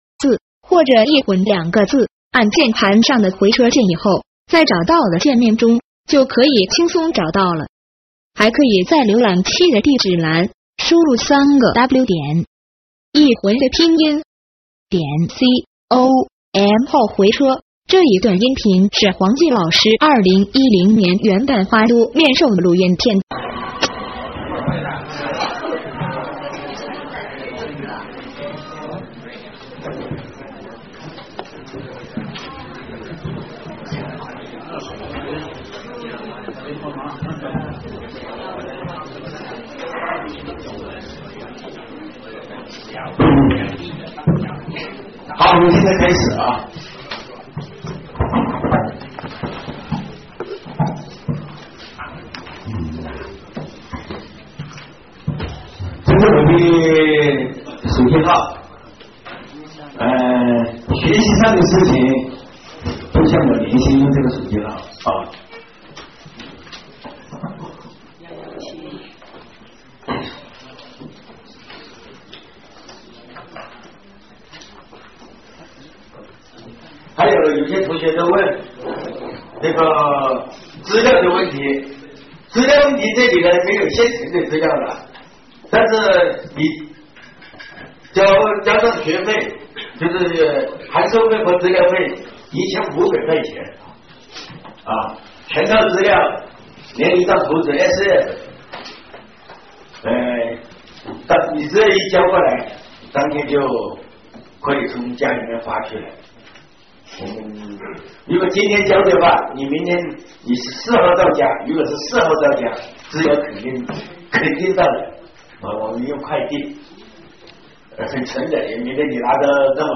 面授录音